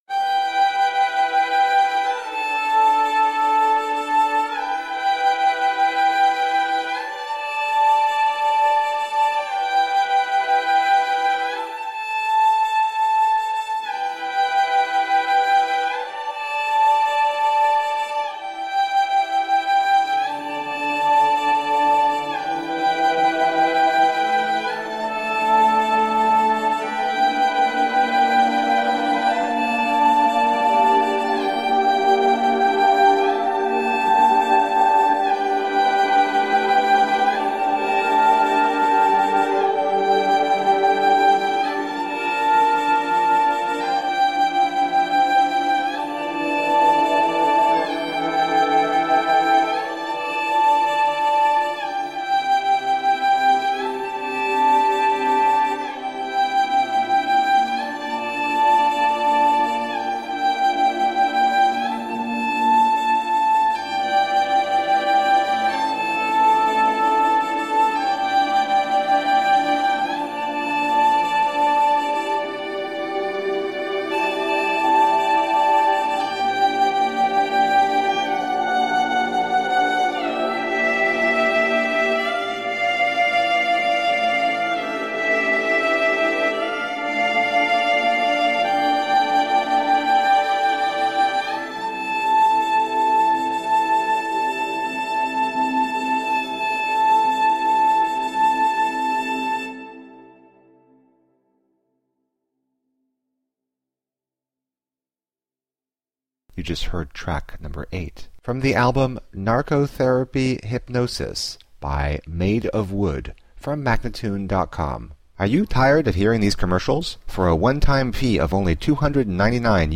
Groove laden chillout funk.
Tagged as: Jazz, Funk, Chillout